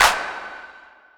Claps
Clp (Topless).wav